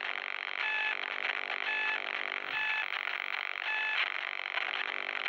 SotF_sfx_player_tracker_noiseLoop_Mono.wav